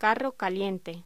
Locución: Carro caliente
voz